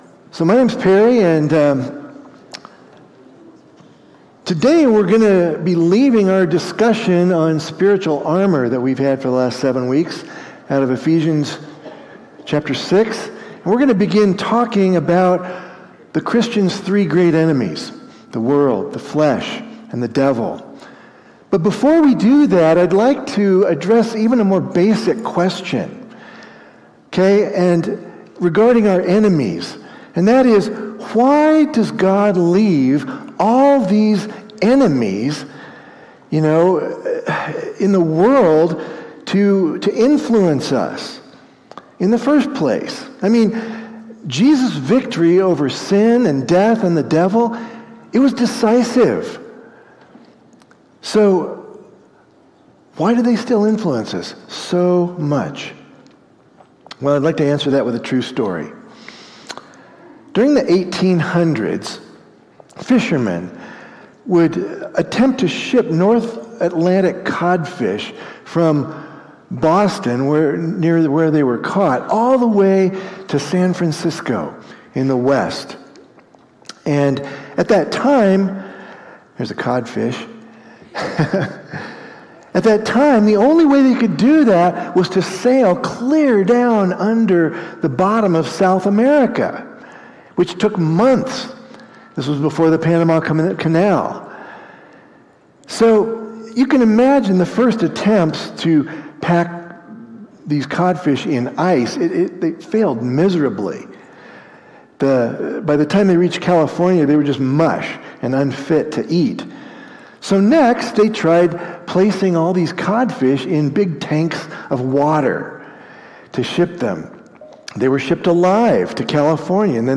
Sermons – Summitview Church